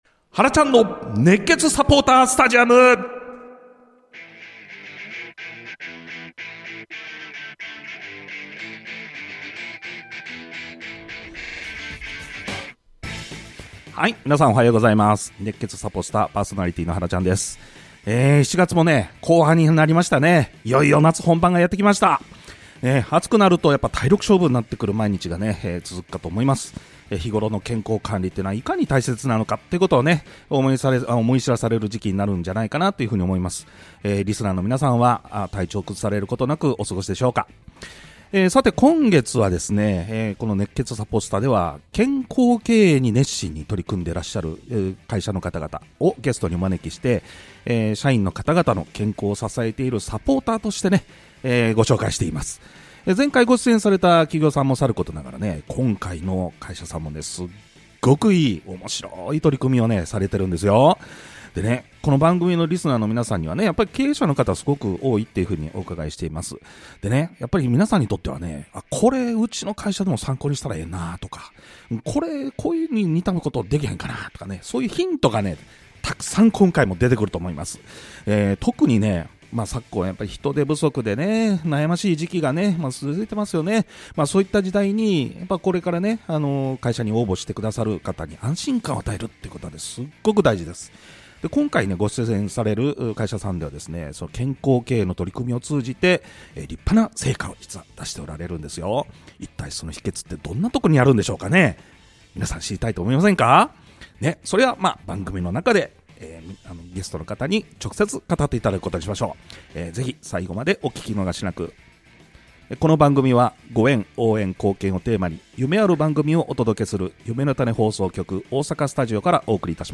インターネットラジオ番組に出演しました！